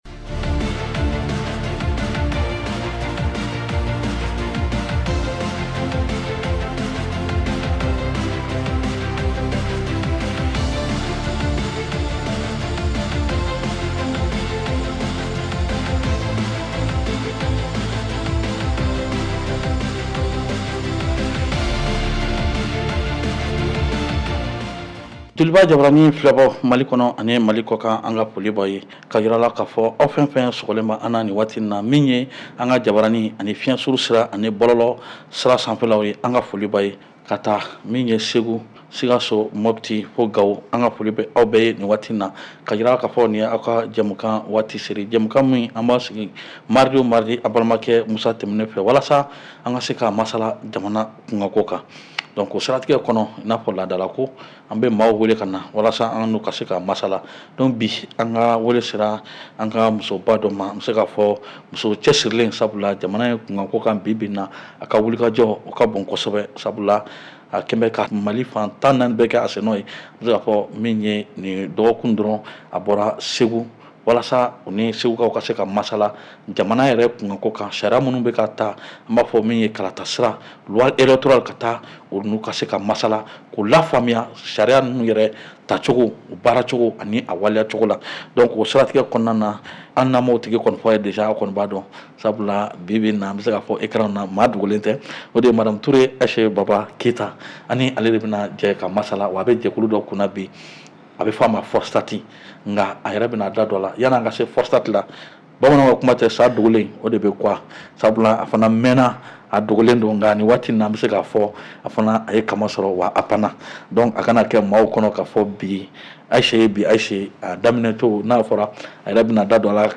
Réécoutez votre émission de débat politique en bambara.